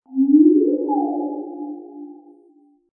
ondes_martent_es.mp3